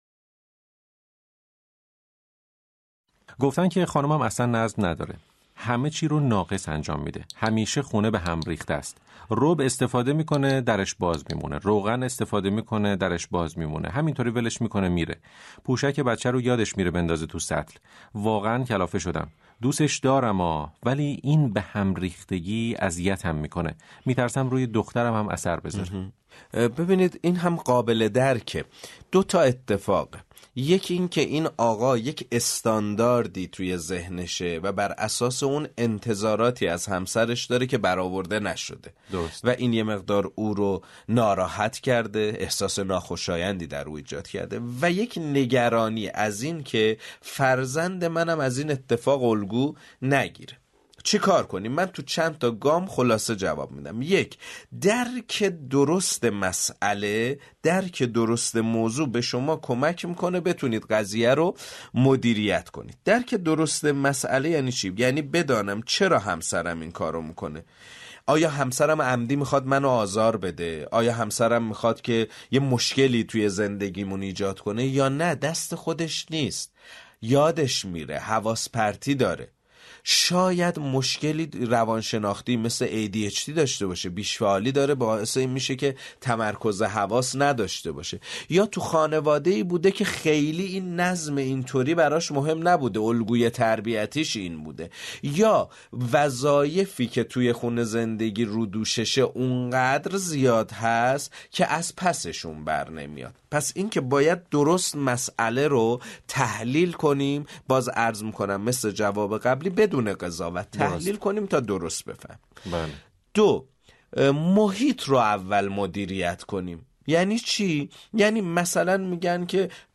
Bu müsahibəni sizinlə də bölüşürük.